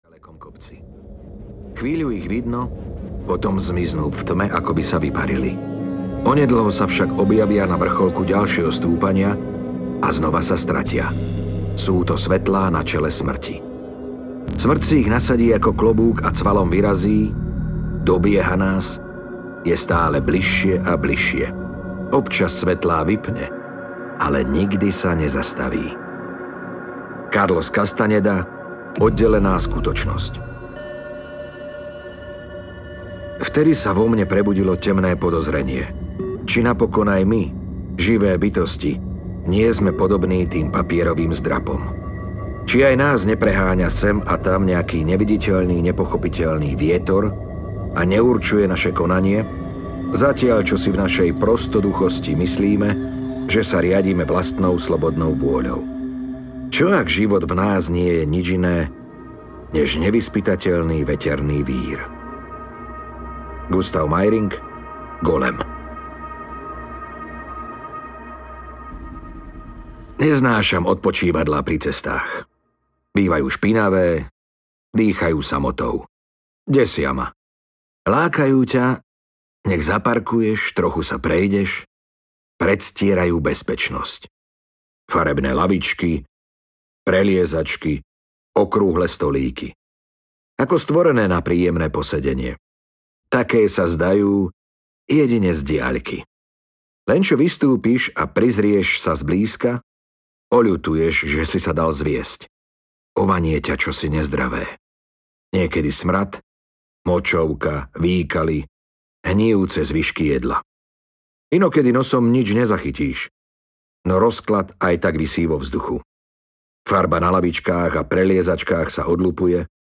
Smršť audiokniha
Ukázka z knihy
smrst-audiokniha